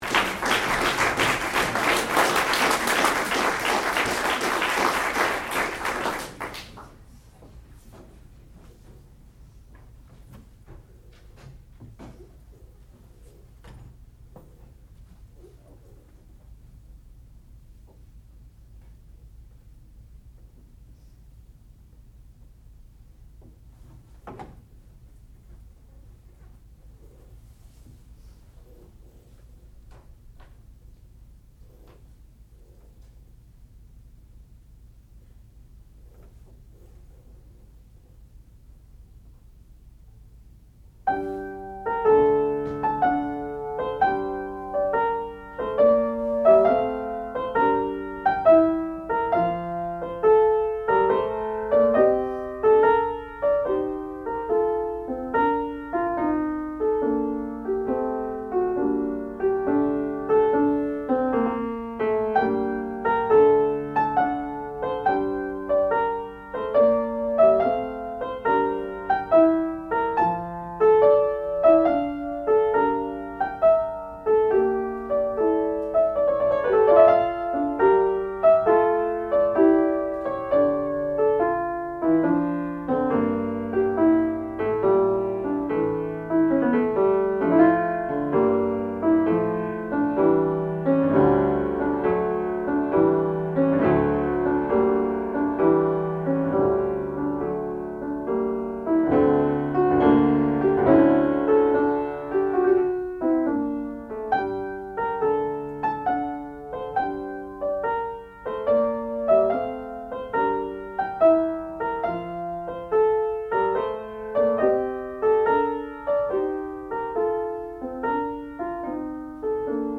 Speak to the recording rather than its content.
Advanced Recital